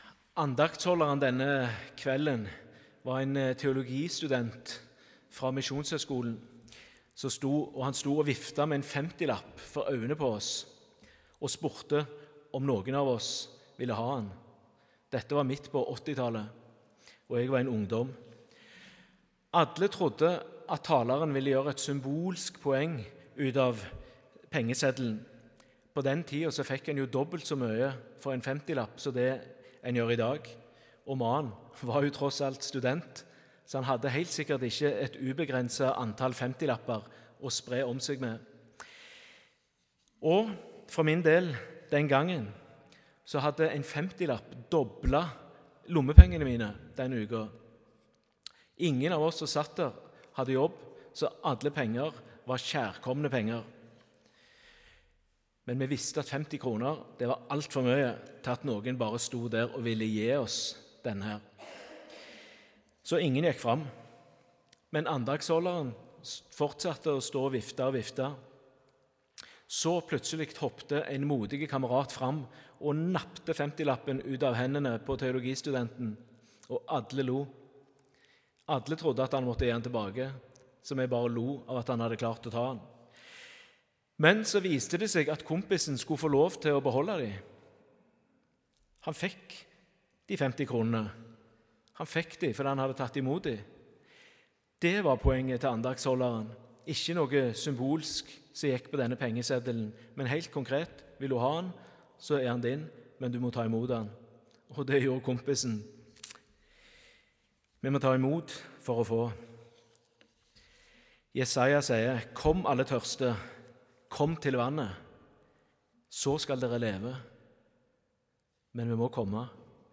Tekstene Evangelietekst: Joh 2,1–11 Lesetekst 1: 1 Mos 1,26–31 Lesetekst 2: Åp 21,1–6 Utdrag fra talen (Hør hele talen HER ) 1.